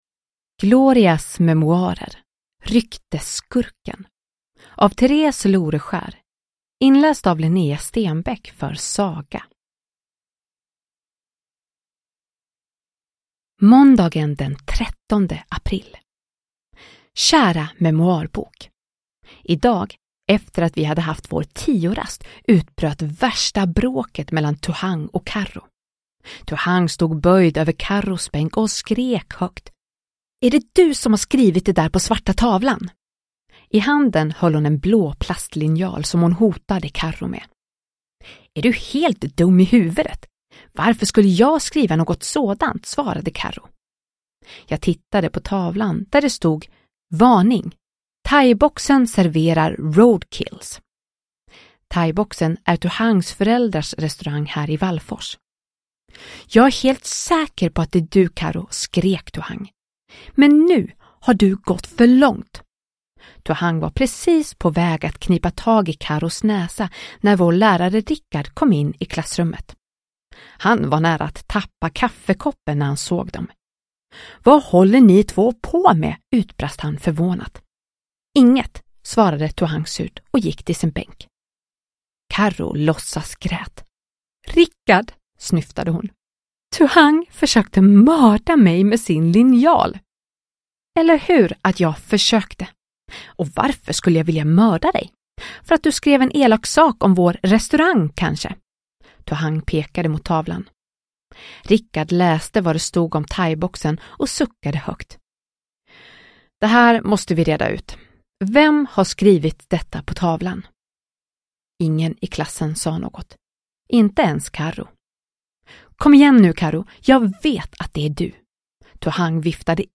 Glorias memoarer: Ryktesskurken – Ljudbok – Laddas ner